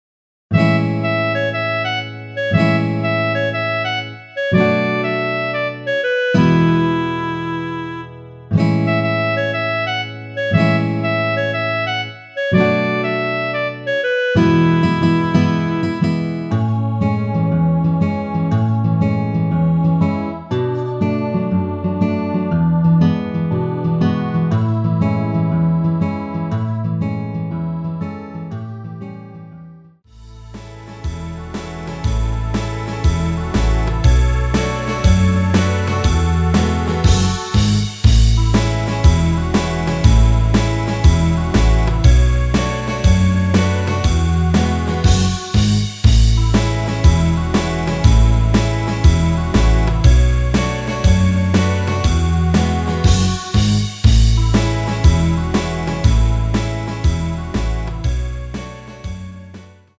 노래방에서 노래를 부르실때 노래 부분에 가이드 멜로디가 따라 나와서
앞부분30초, 뒷부분30초씩 편집해서 올려 드리고 있습니다.
중간에 음이 끈어지고 다시 나오는 이유는